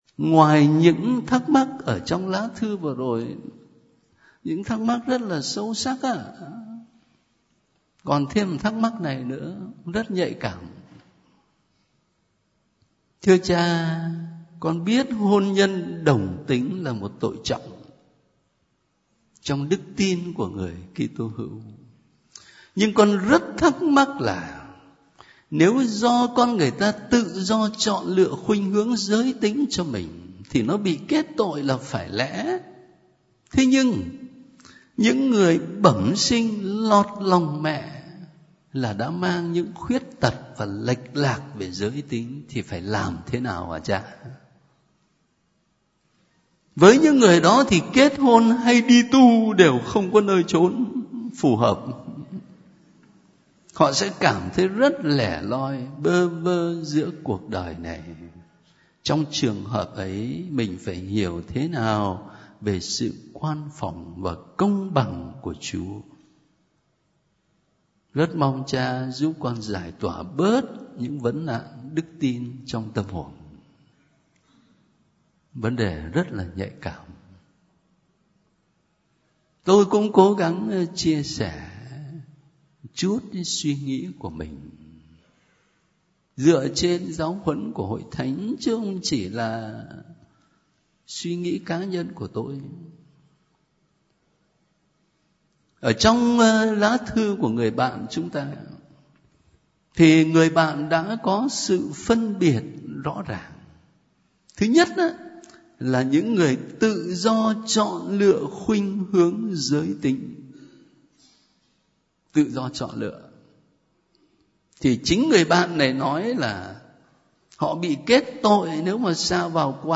Bạn đang nghe audio Vấn nạn đồng tính - Được thể hiện qua Gm. Phêrô Nguyễn Văn Khảm.
* Thể loại: Thắc mắc sống đạo
7.giaidap_VanNanDongTinh_gmKham.mp3